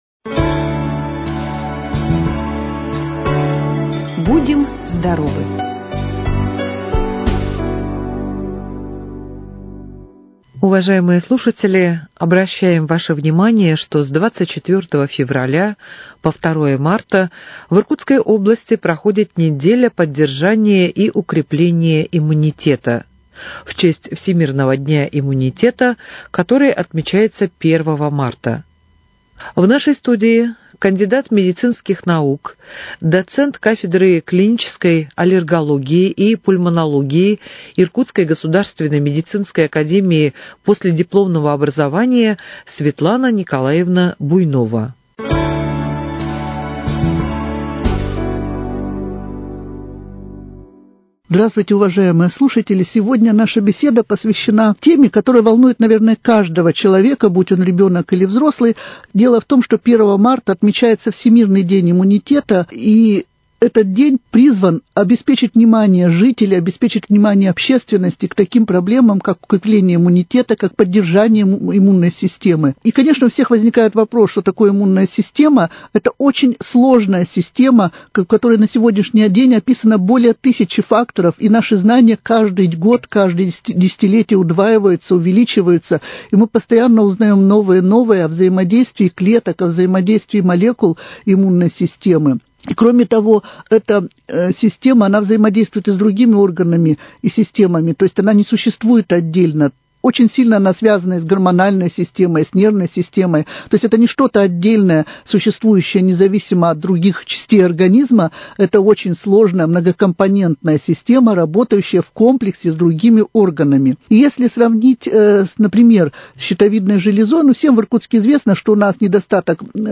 В нашей студии